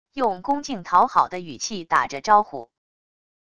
用恭敬讨好的语气打着招呼wav音频